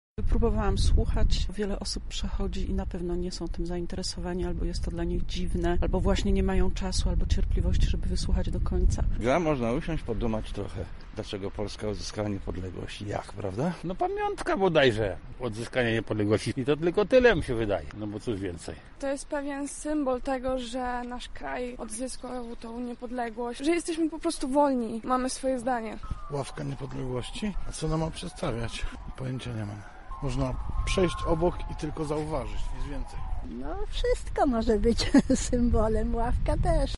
Nasza reporterka zapytała mieszkańców Lublina co sądzą o takim przedsięwzięciu: